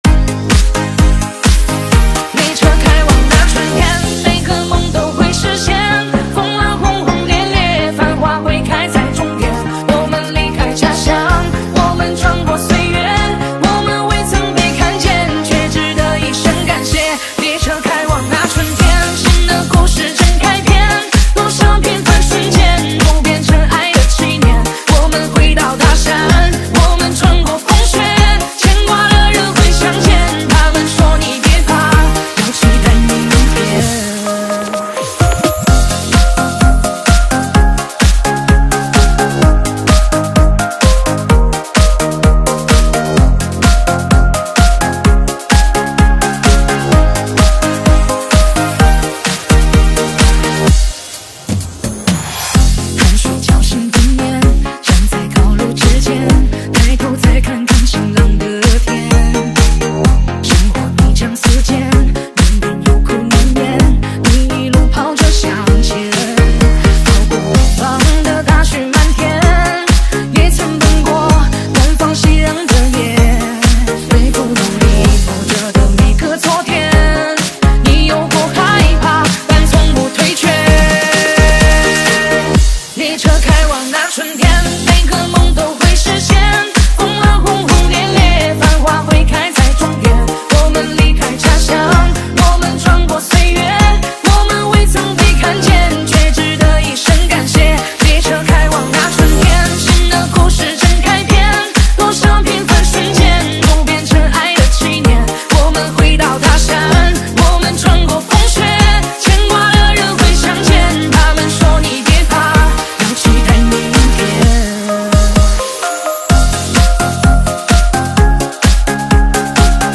Myvi GM3.5 Custom Twin Sport sound effects free download
Myvi GM3.5 Custom Twin Sport Muffler